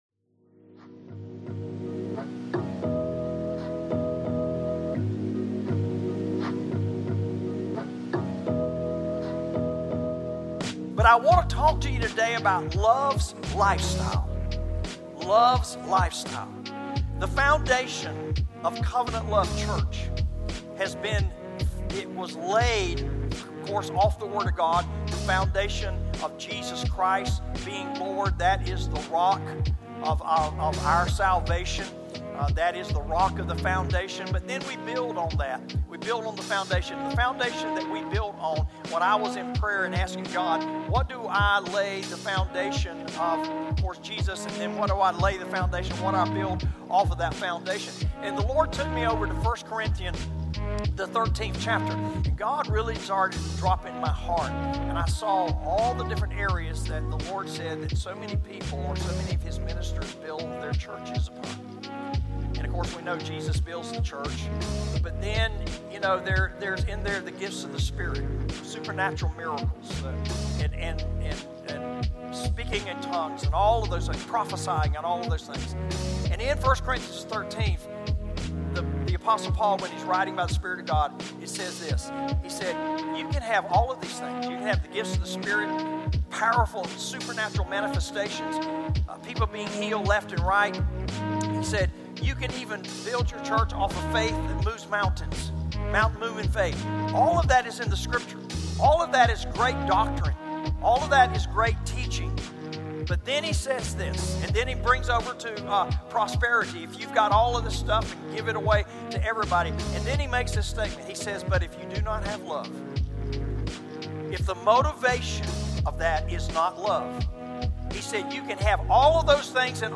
Pastor Lo-fi Mix 01